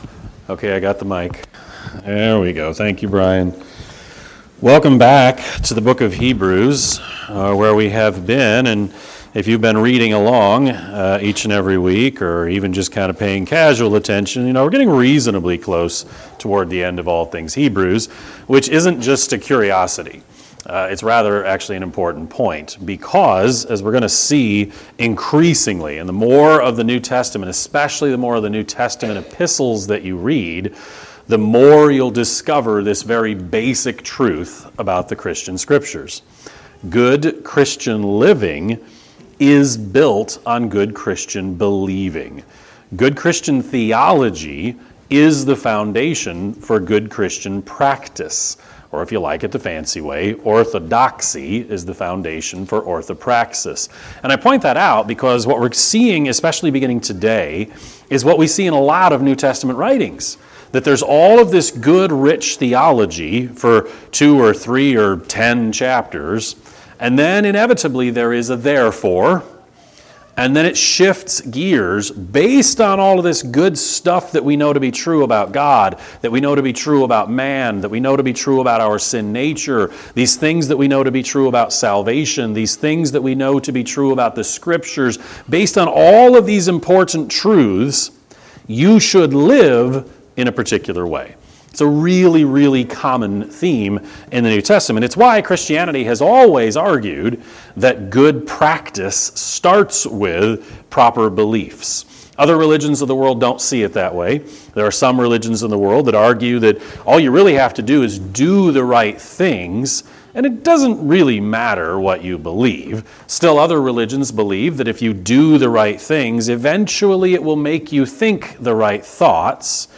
Sermon-11-2-25-Edit.mp3